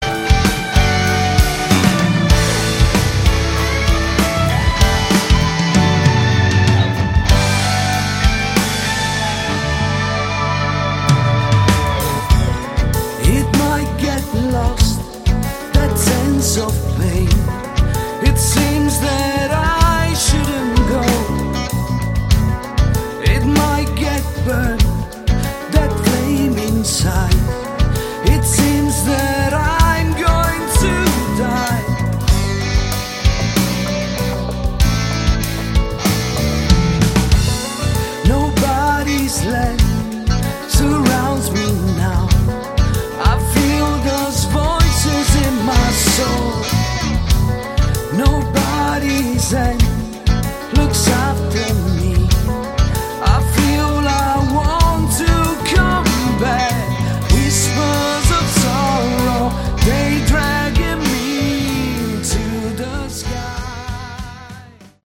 Category: Melodic Rock
lead and backing vocals
guitars, keyboards, violin